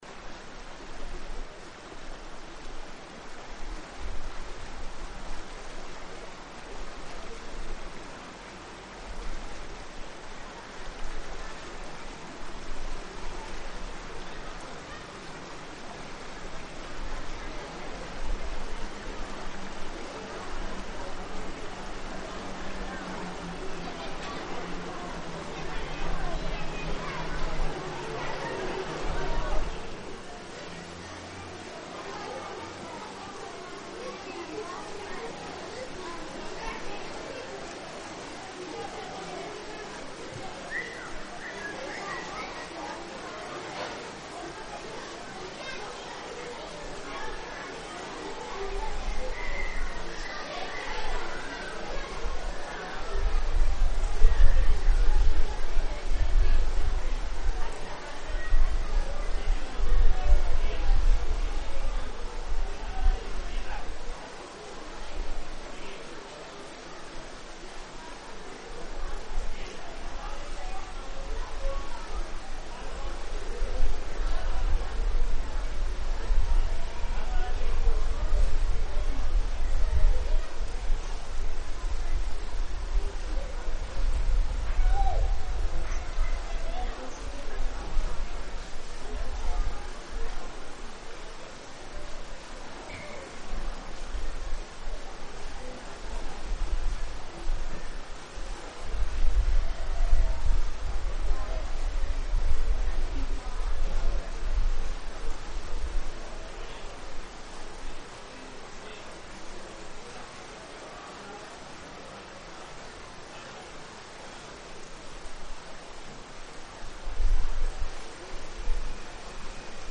sound of the water , sound of animals , water , birds , crowd
El sonido del agua, de los pájaros y del bullicio callejero debieron ser elementos característicos de su paisajes sonoro.
Soundscapes in Espinosa's bridge.